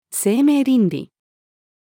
生命倫理-female.mp3